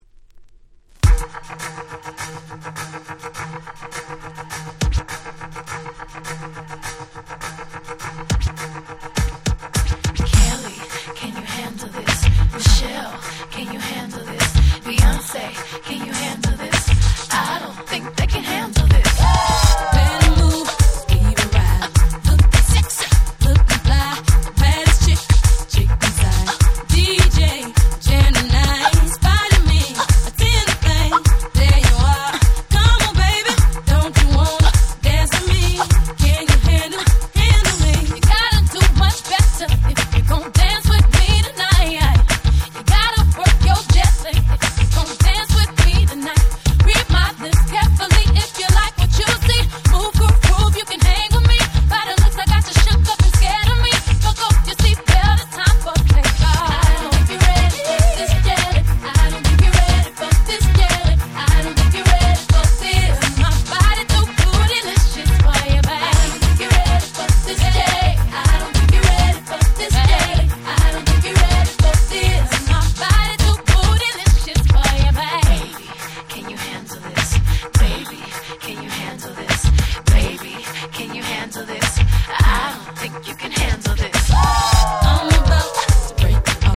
01' Super Hit R&B !!
3人の魅力を思う存分に堪能できる最高のダンスチューンです。